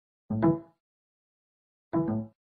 • Качество: высокое
Windows 7 вставили и извлекли флешку